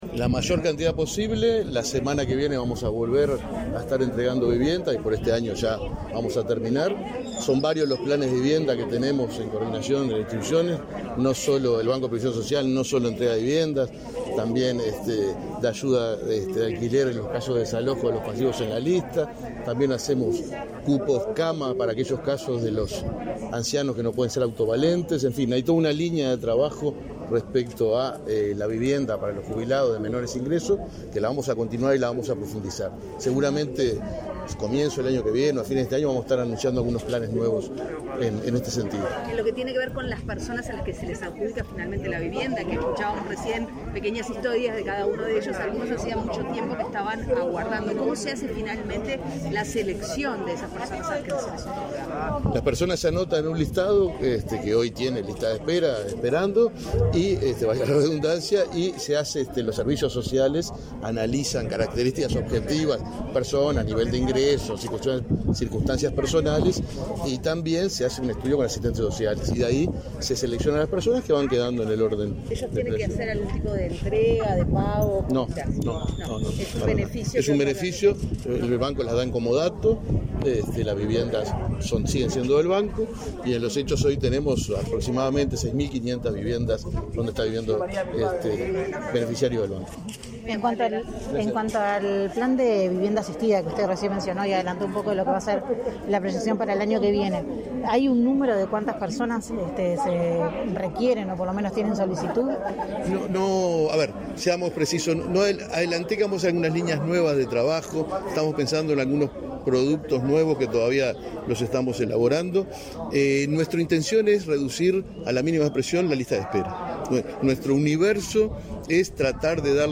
Declaraciones a la prensa del presidente del BPS, Alfredo Cabrera
Declaraciones a la prensa del presidente del BPS, Alfredo Cabrera 30/11/2021 Compartir Facebook X Copiar enlace WhatsApp LinkedIn Tras participar en la entrega de viviendas a jubilados y pensionistas de Montevideo, este 30 de noviembre, el presidente del Banco de Previsión Social (BPS) efectuó declaraciones a la prensa.